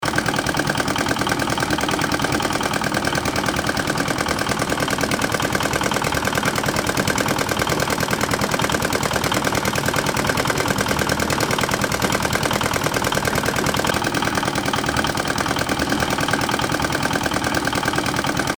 Рев тракторного двигателя на холостом ходу